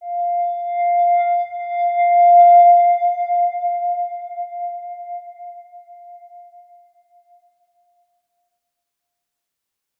X_Windwistle-F4-ff.wav